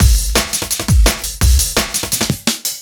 cw_170_Ereaser.wav